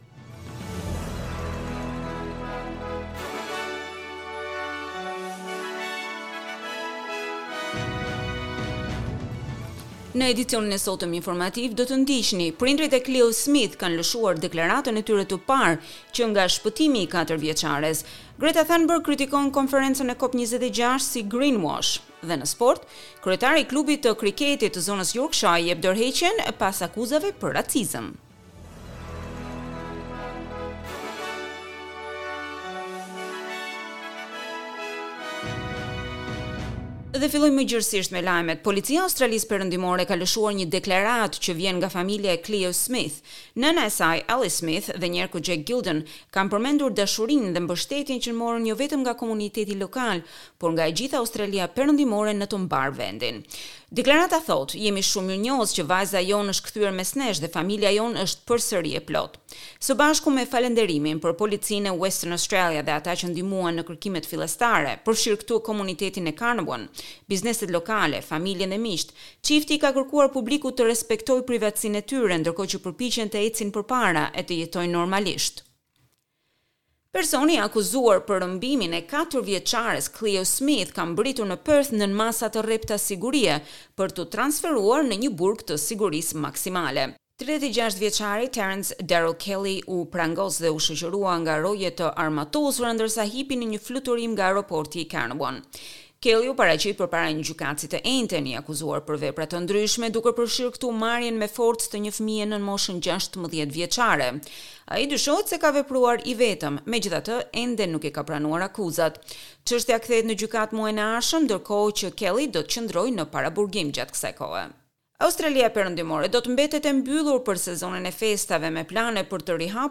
SBS News Bulletin in Albanian - 6 November 2021